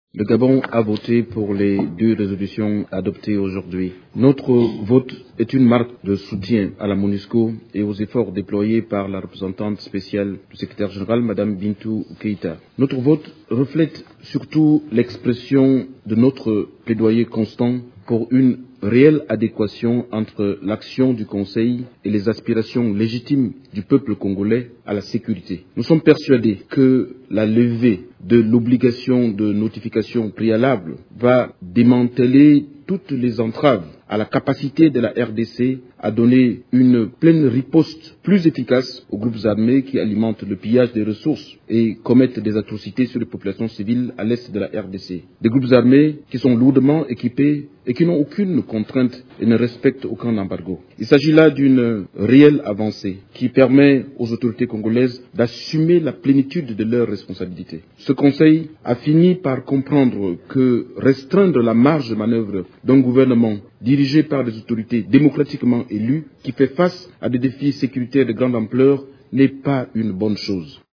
Autre réaction, celle de Michel-Xavier Biang, représentant permanent du Gabon et Président du Comité des sanctions du Conseil de Sécurité mis en place par la résolution 1533, qui a salué cette résolution au cours de la séance du Conseil de sécurité. Il estime que cette décision va lever toutes les entraves à la capacité de la RDC à donner une riposte plus efficace aux groupes armés qui commettent notamment des atrocités dans l’Est du pays